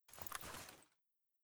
aug_holster.ogg